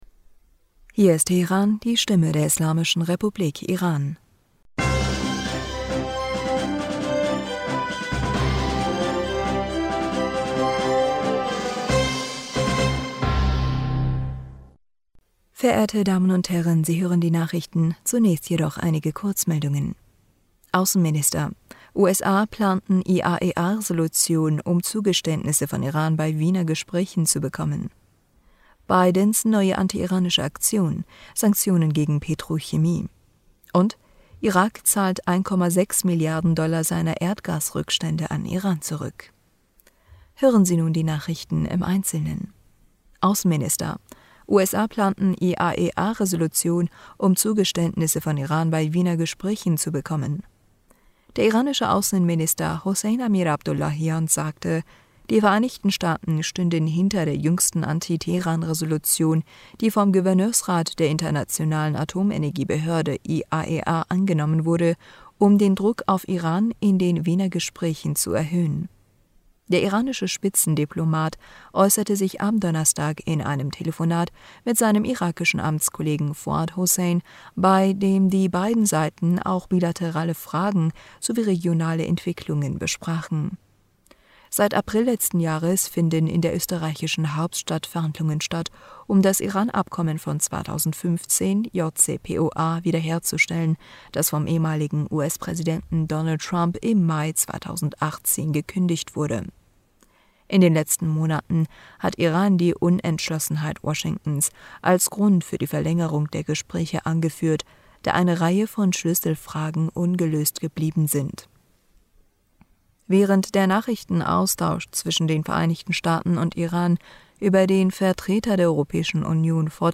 Nachrichten vom 17. Juni 2022
Die Nachrichten von Freitag dem 17. Juni 2022